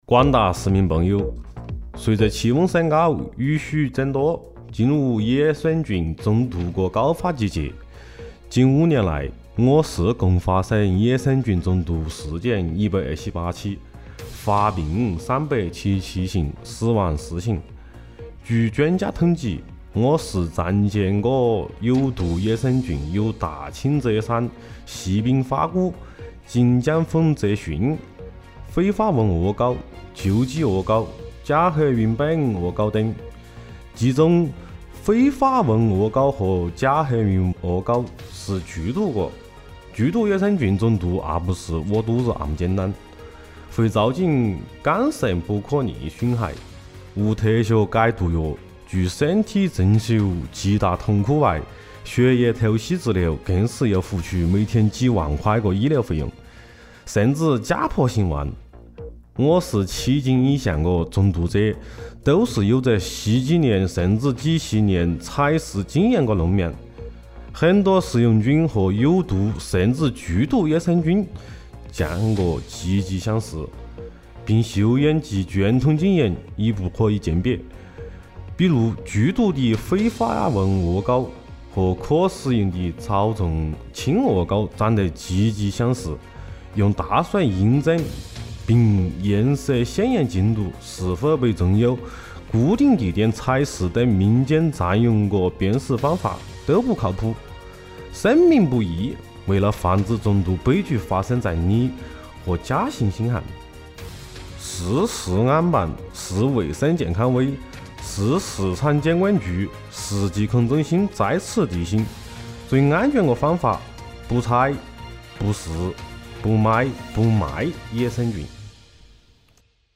大喇叭音频